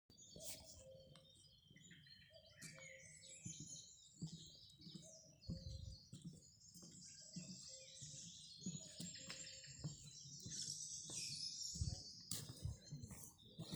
Birds -> Cuckoos ->
Common Cuckoo, Cuculus canorus
StatusSinging male in breeding season